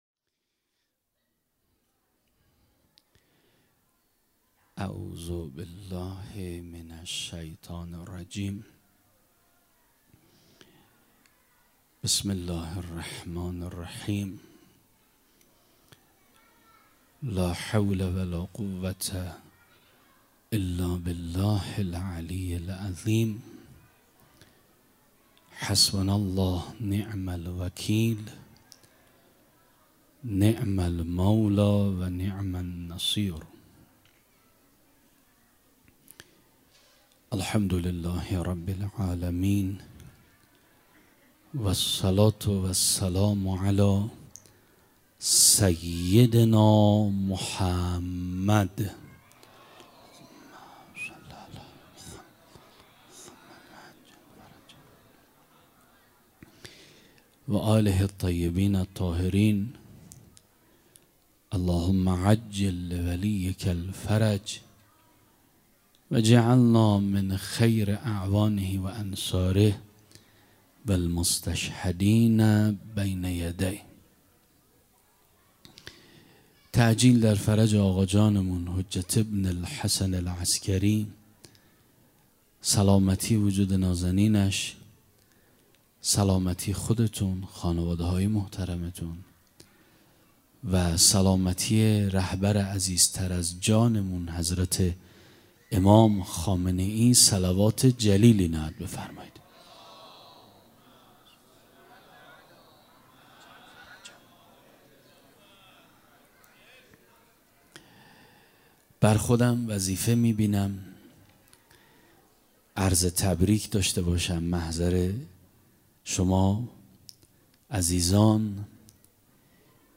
صوت مراسم ولادت حضرت مهدی(عج)